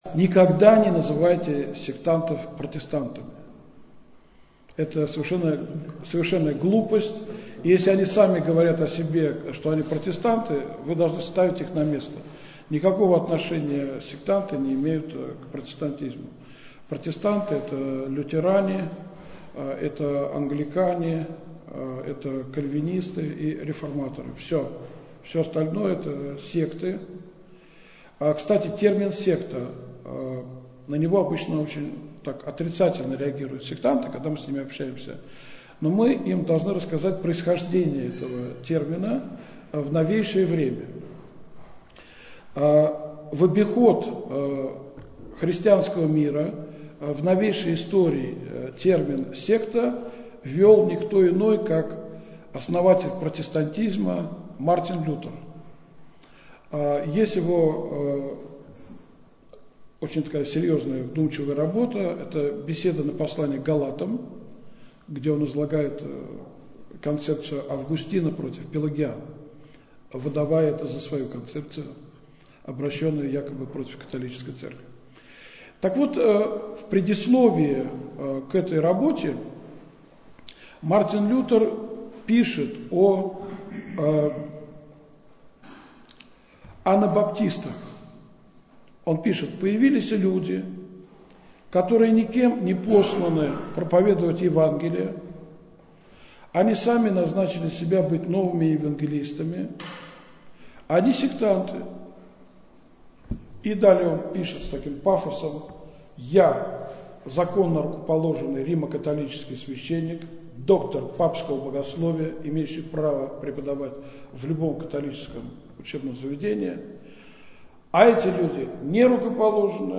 В этой беседе